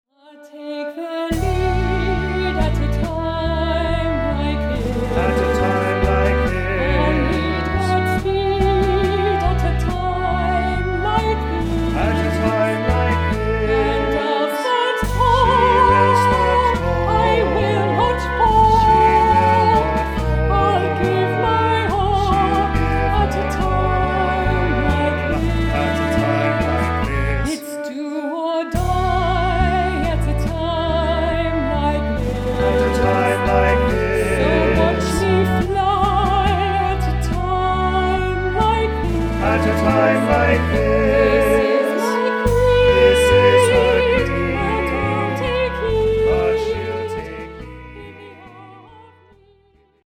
Sample from the Vocal CD